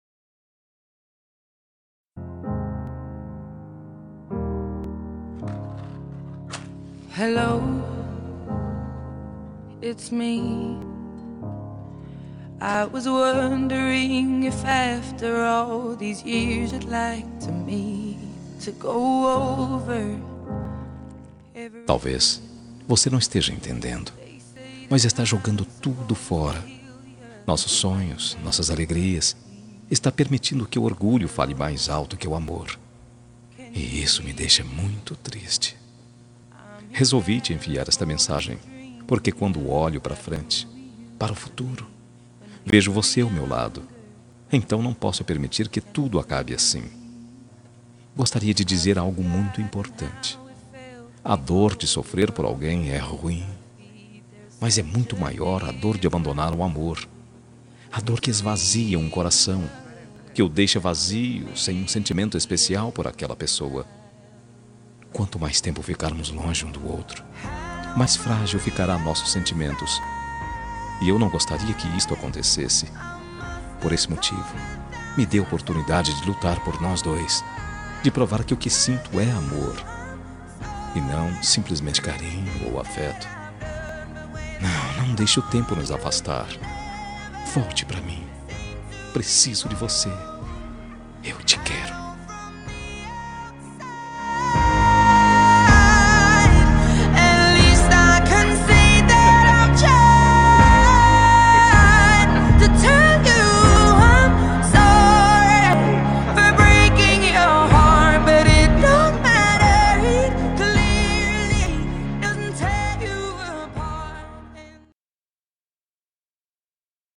Telemensagem de Reconciliação Romântica – Voz Masculina – Cód: 958